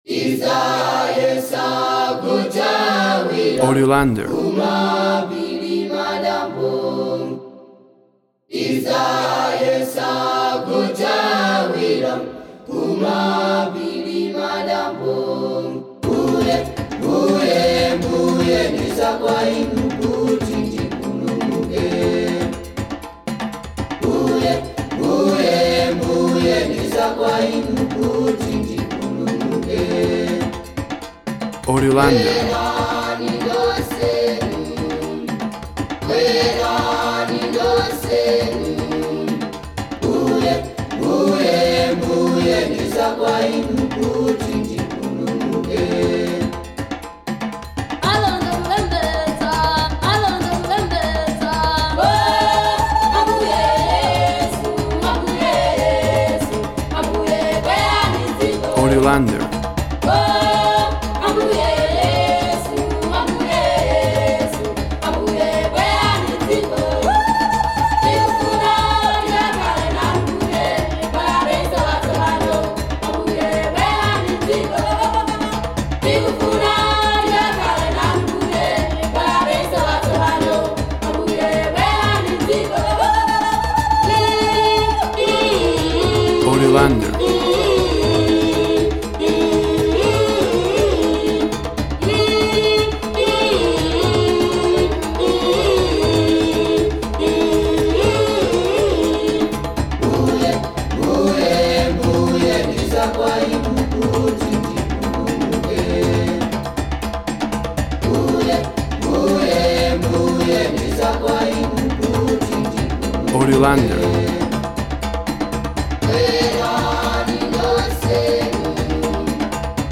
African Choir with percusion, rhythmic song.
WAV Sample Rate 24-Bit Stereo, 44.1 kHz
Tempo (BPM) 120